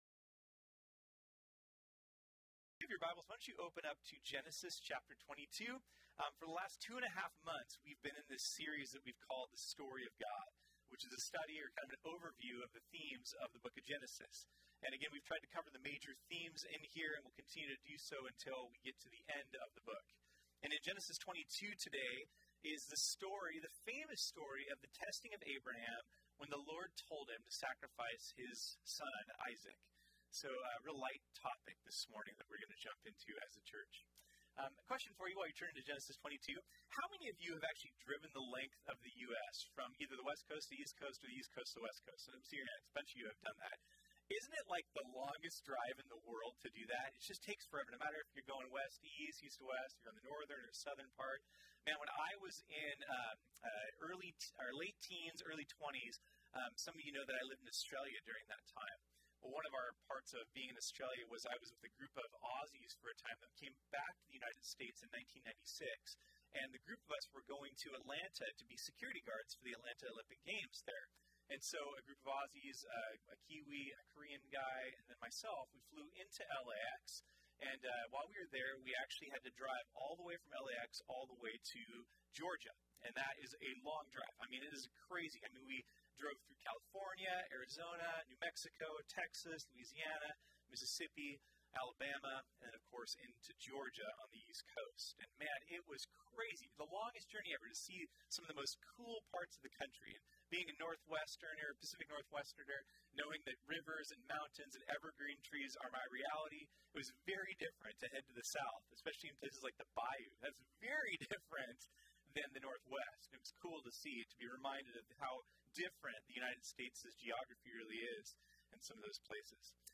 This sermon was originally preached on Sunday, March 10, 2019.